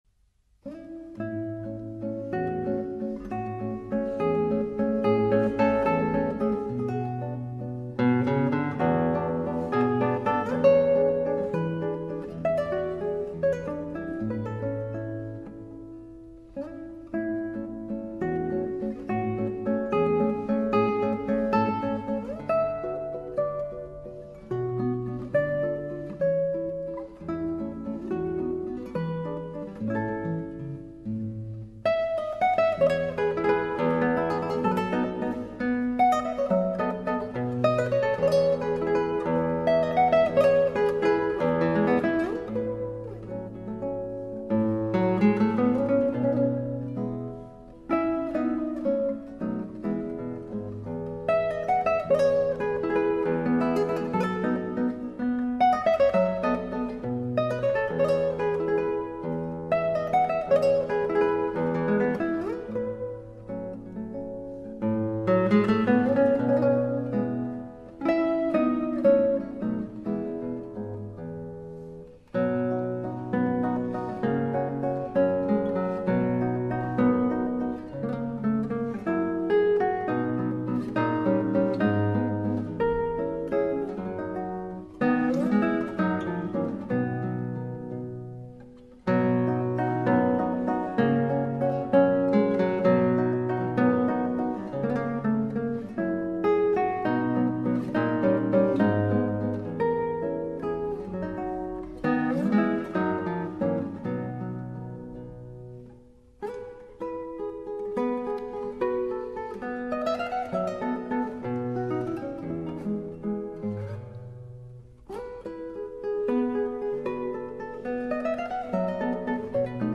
Musical accompaniment: Gran Vals Integral de Guitarra composed by Francisco Tárrega in 1902 and performed by David Russell.
gran-vals-integral-de-guitarra-composed-by-francisco-tarrega-perf-david-russell.mp3